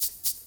SHAKER LP1-R.wav